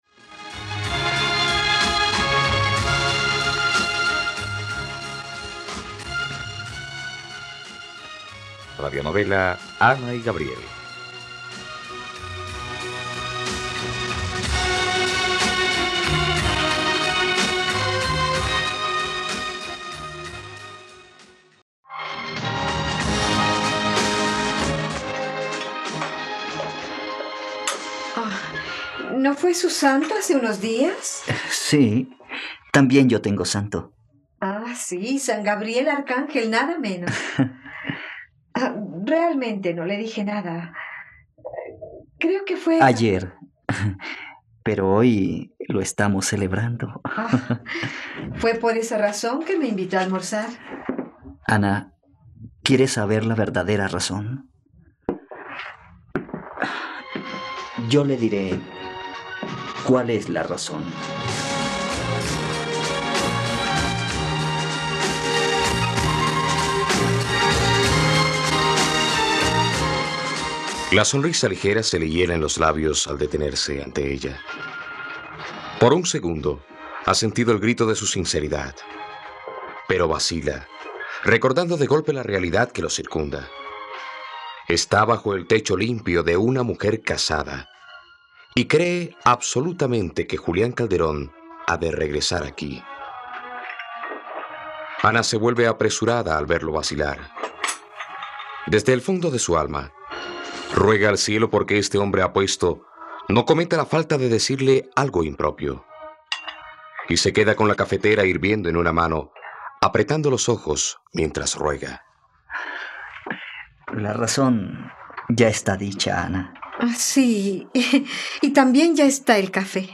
..Radionovela. Escucha ahora el capítulo 20 de la historia de amor de Ana y Gabriel en la plataforma de streaming de los colombianos: RTVCPlay.